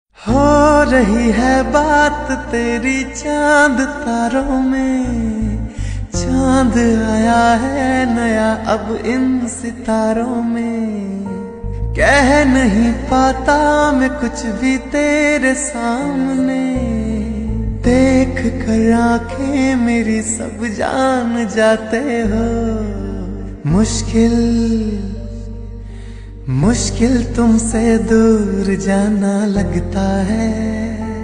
Punjabi Songs
• Simple and Lofi sound
• Crisp and clear sound